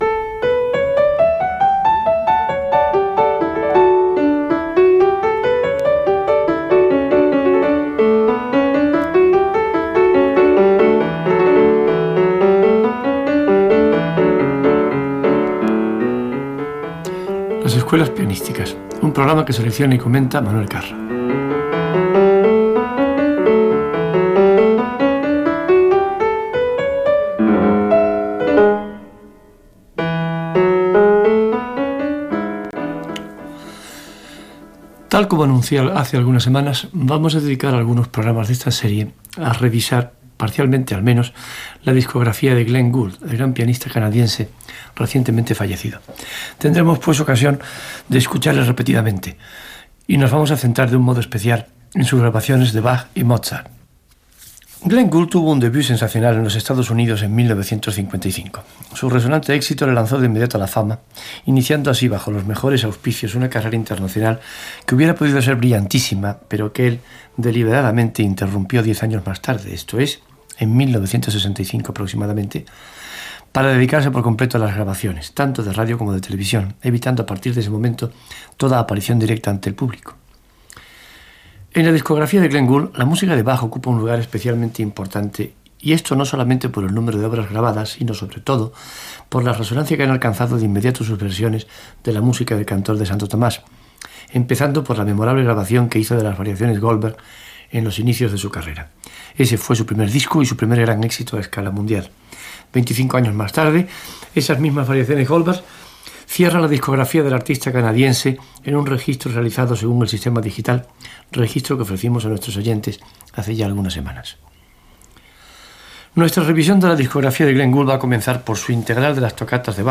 Sintonia, presentació de l'espai dedicat al pianista Glenn Gould
Musical
FM